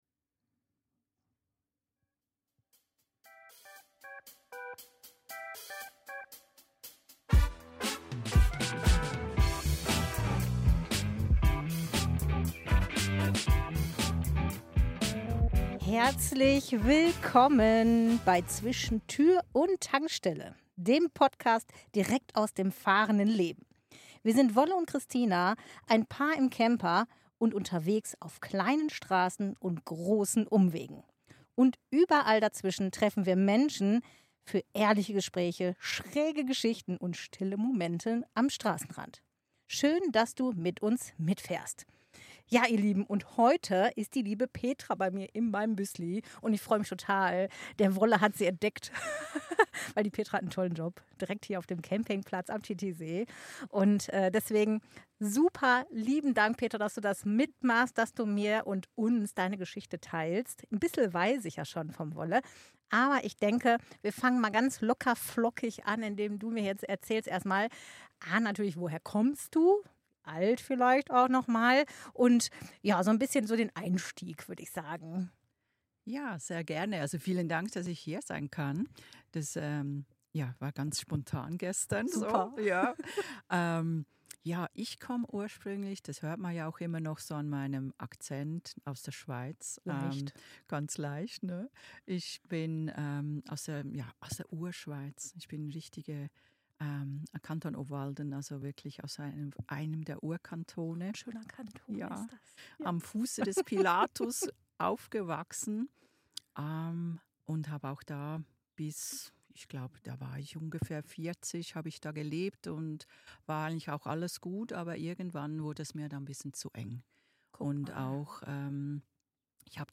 Ohne Planung, einfach spontan, Kopfhörer auf, Mikro in die Hand und ab geht die Post.